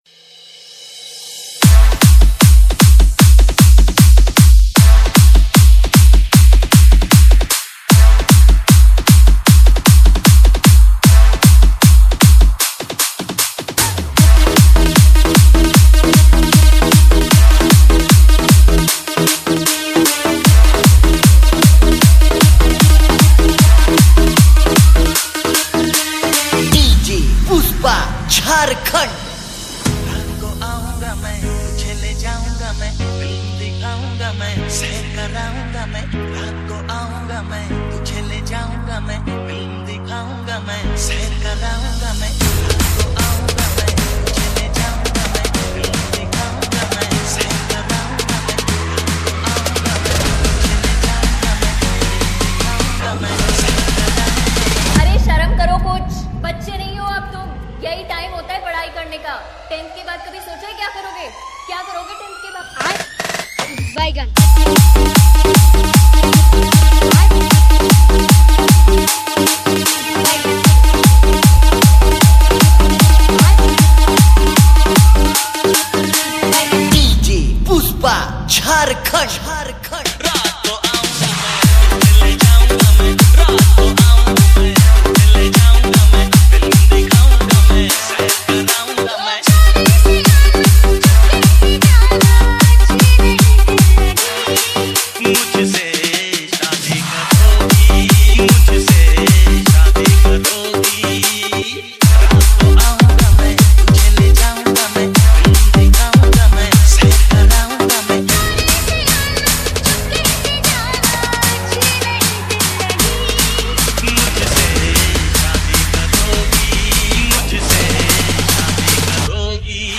Category : Old Is Gold Remix Song